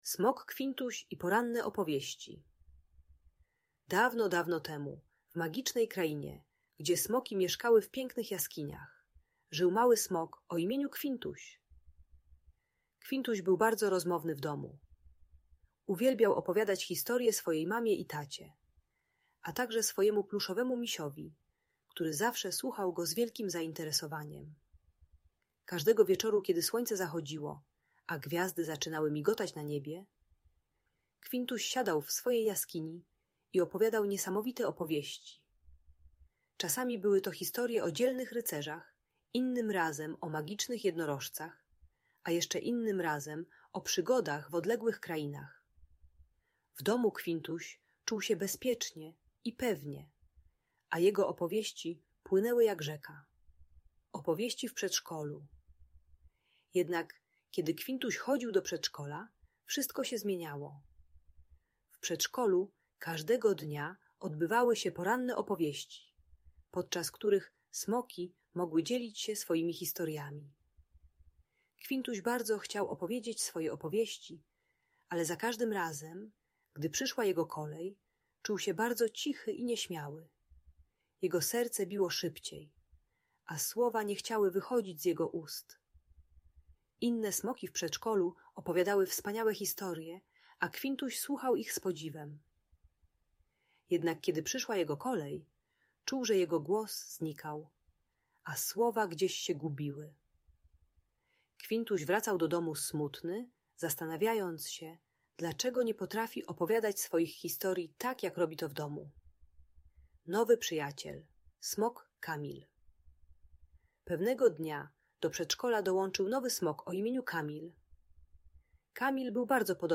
Historia Kwintusia: Odwaga i Przyjaźń Małego Smoka - Audiobajka